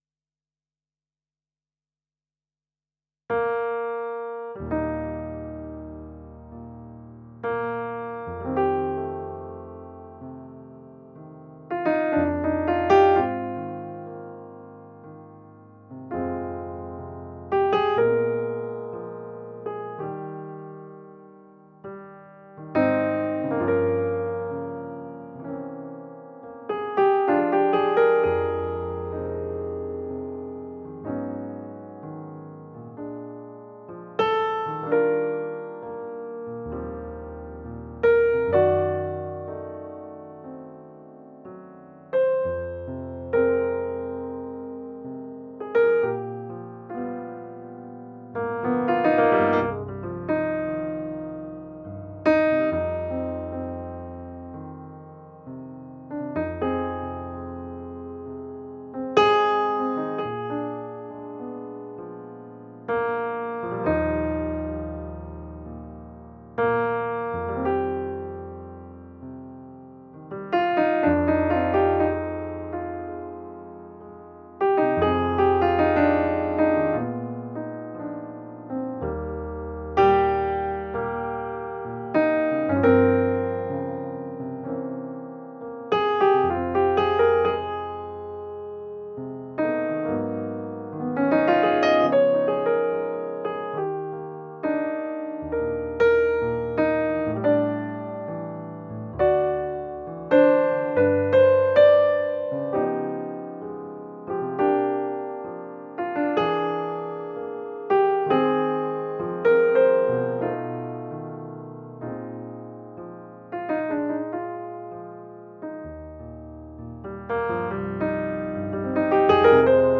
NordPiano-Grand-Lady-D.mp3